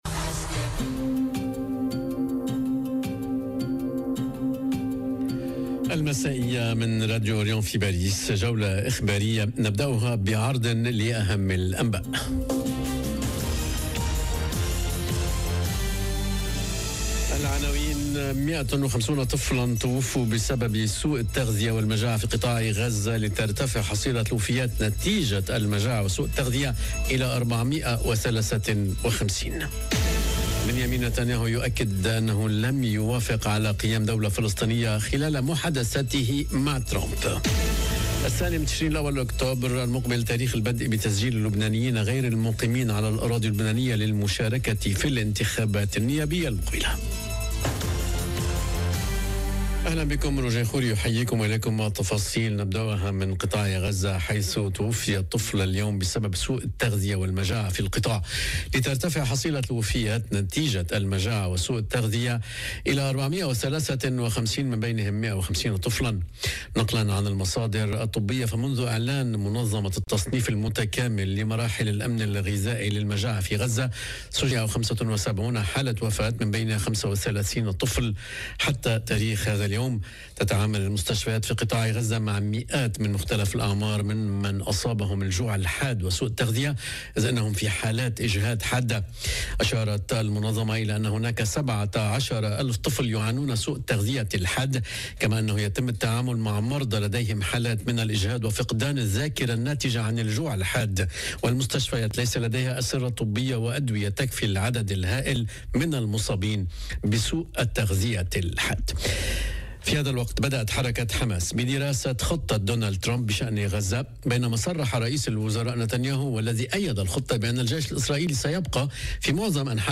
نشرة أخبار المساء: 150 طفلا توفوا بسبب سوء التغذية والمجاعة في قطاع غزة، لترتفع حصيلة الوفيات نتيجة المجاعة وسوء التغذية إلى 453.... - Radio ORIENT، إذاعة الشرق من باريس